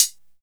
Hat (43).wav